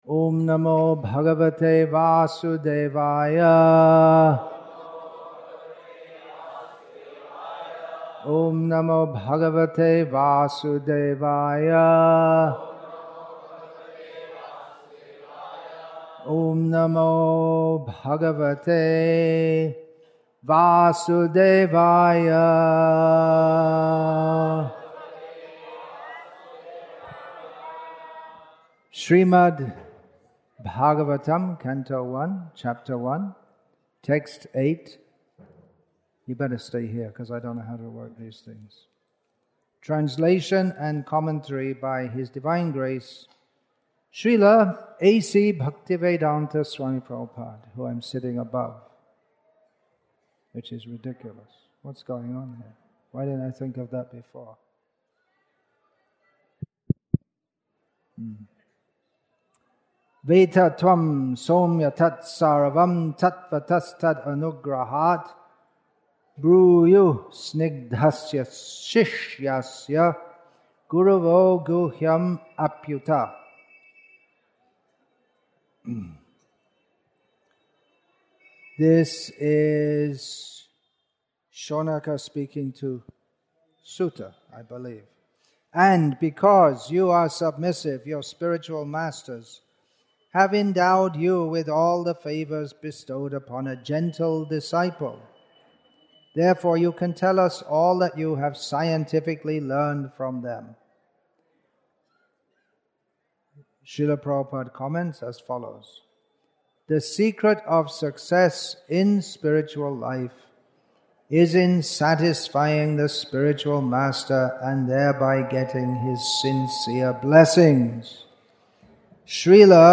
Vyasa-puja Address